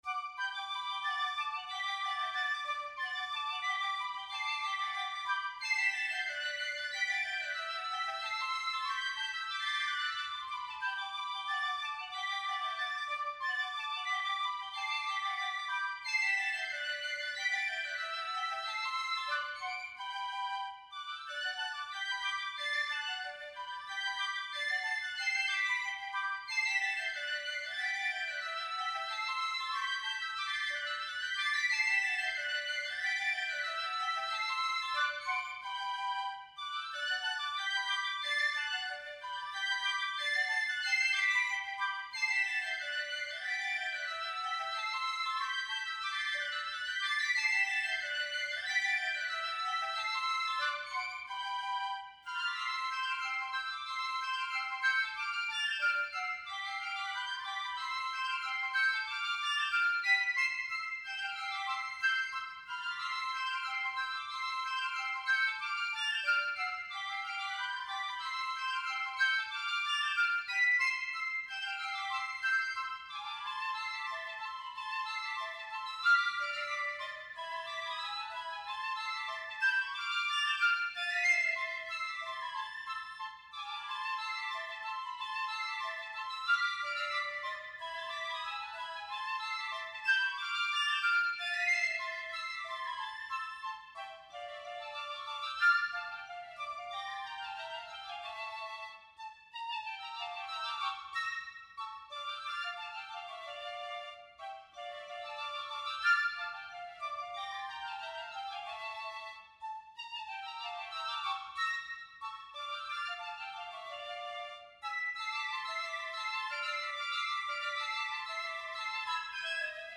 Eigene Kompositionen und Arrangements für Basler Piccolo
Dreistimmiger Marsch für Basler Piccoli.